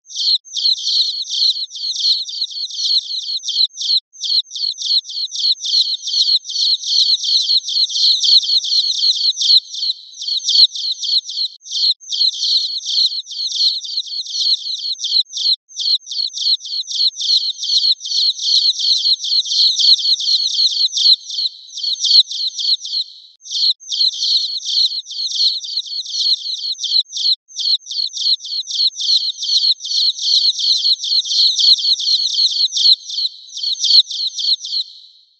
かなりの数のヒヨコが「ぴよぴよぴよぴよ・・・」と可愛く鳴いています。